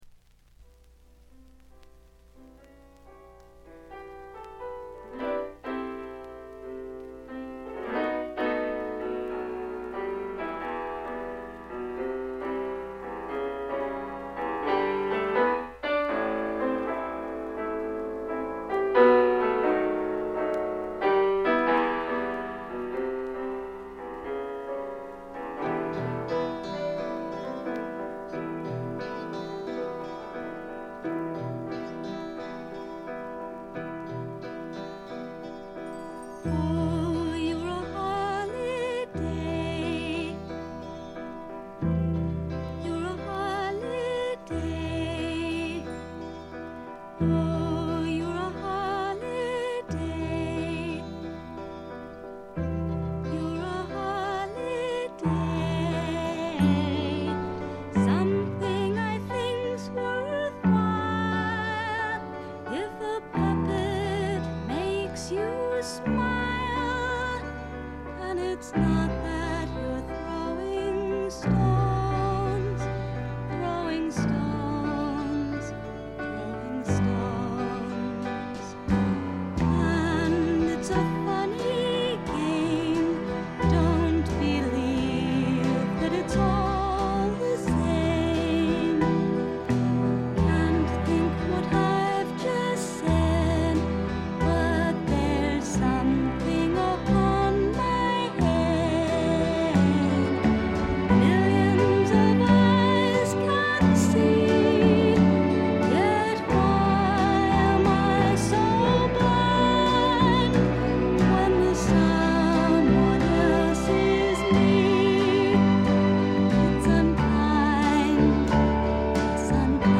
ところどころで軽いチリプチ。
ドリーミーなアメリカン・ガールポップの名作！
試聴曲は現品からの取り込み音源です。